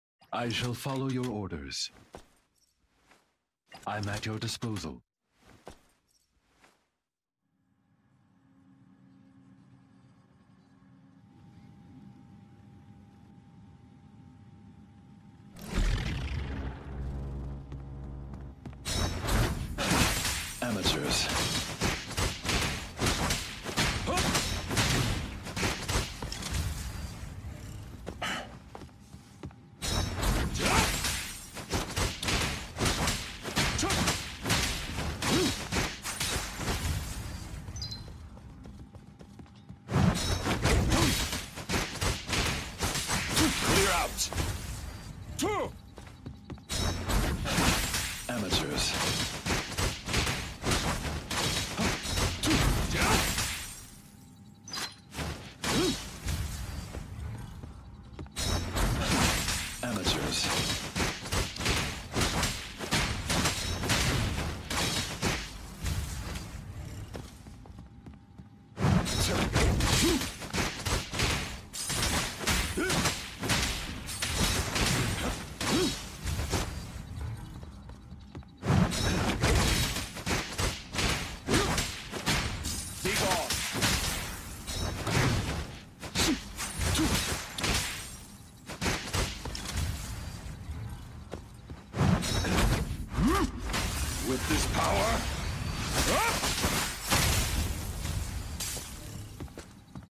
von Lycaon English voice game sound effects free download